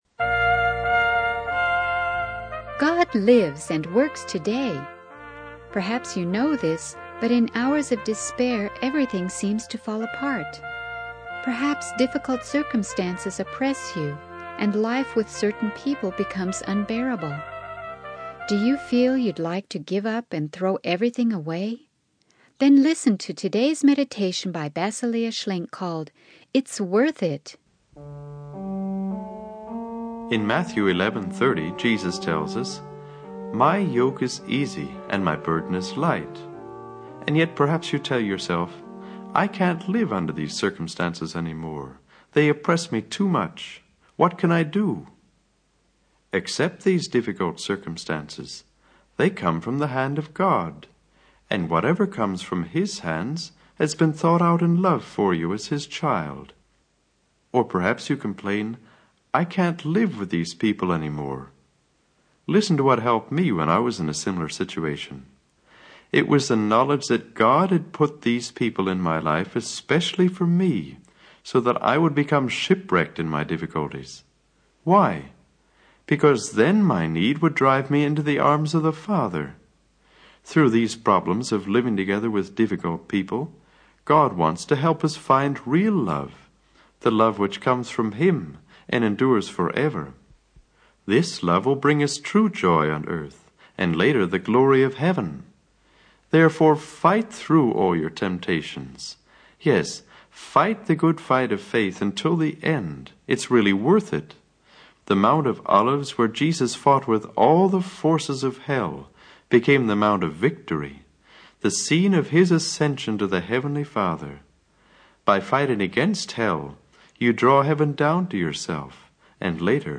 In this sermon by Basilia Schlenk titled 'It's Worth It,' she encourages listeners to persevere through difficult circumstances and relationships.